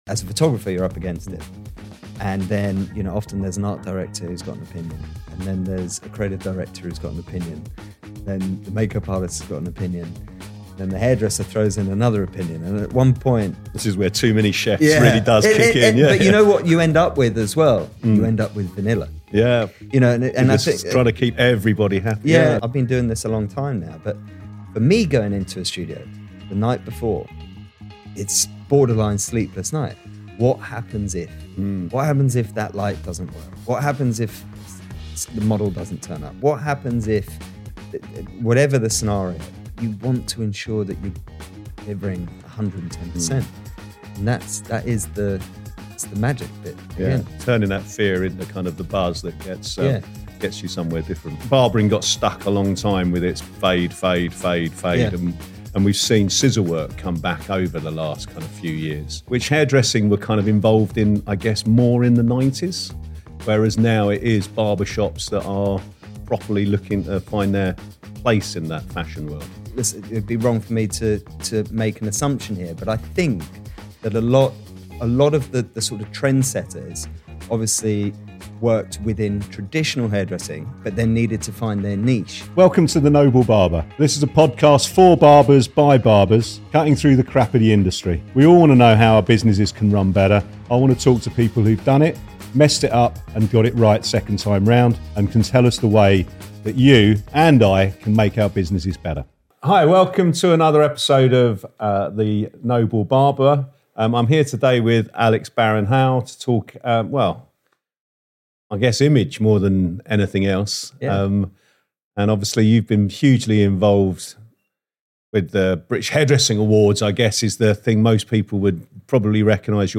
Joined weekly by industry professionals to talk about barbering, becoming and maintaining a barber shop, hiring, employment, money and cutting through the crap of the industry.